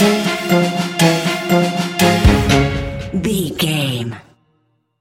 Aeolian/Minor
C#
Slow
scary
ominous
eerie
percussion
brass
synthesiser
strings
instrumentals
horror music